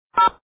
dtmf.ogg